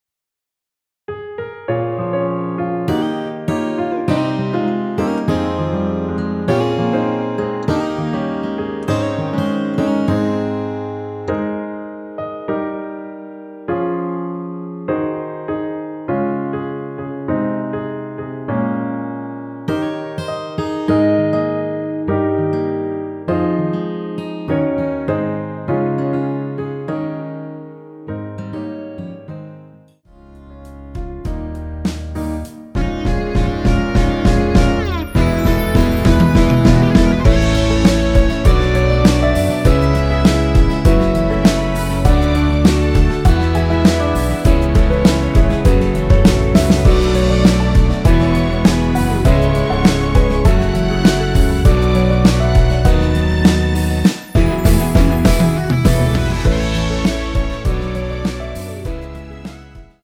원키에서(-1)내린 MR입니다.
Ab
앞부분30초, 뒷부분30초씩 편집해서 올려 드리고 있습니다.
중간에 음이 끈어지고 다시 나오는 이유는